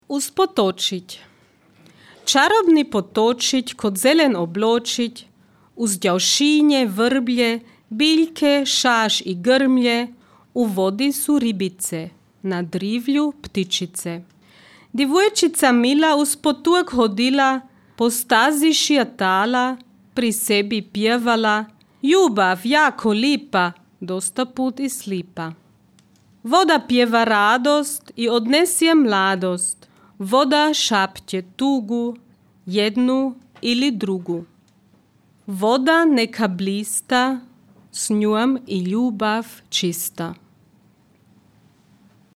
čita: